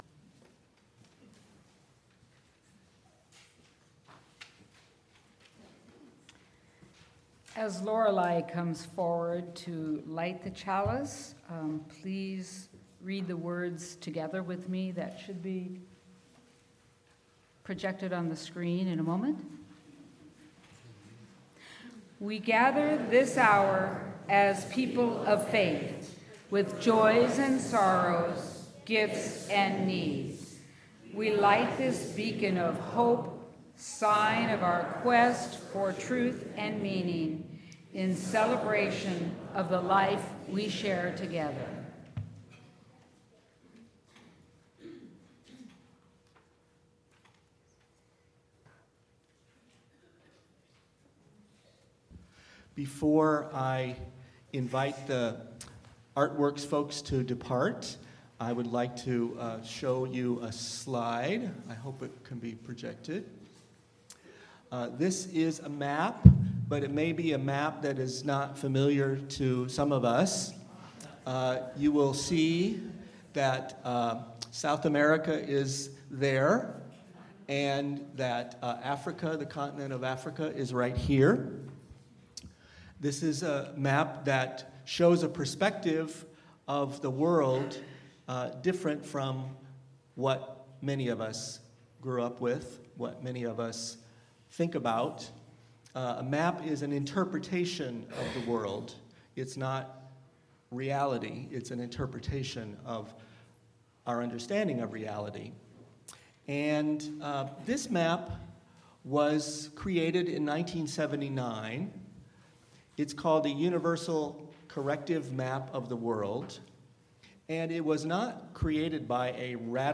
Sermon-De-centering-White-Supremacy-Culture.mp3